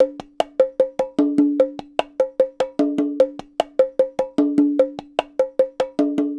Bongo 04.wav